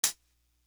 D Elite Hat.wav